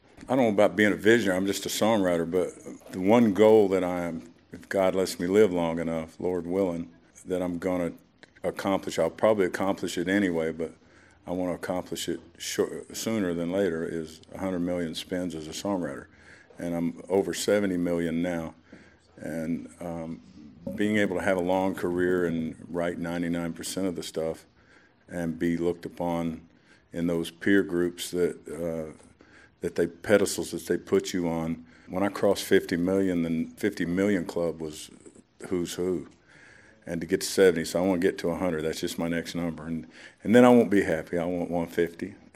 Backstage at the now defunct American Country Awards (ACAs) in 2010, Toby Keith talked about what he wanted to accomplish sooner rather than later.